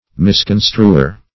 Misconstruer \Mis*con"stru*er\, n.